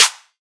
DDW Snare 4.wav